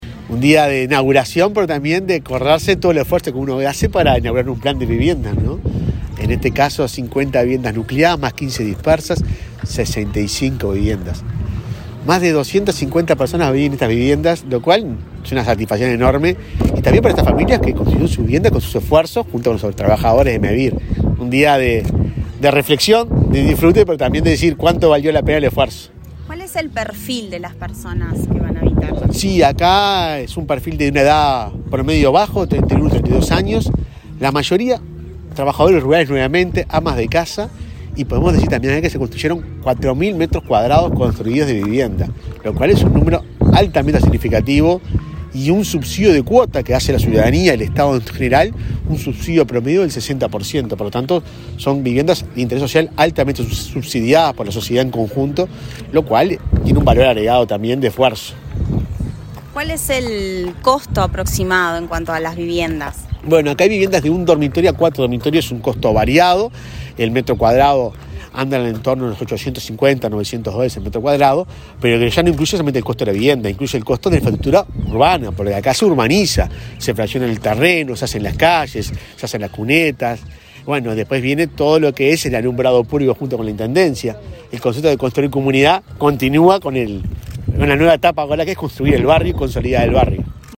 Declaraciones del presidente de Mevir, Juan Pablo Delgado